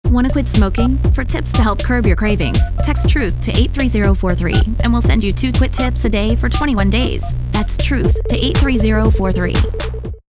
Also, attached are radio station produced advertisements for our program: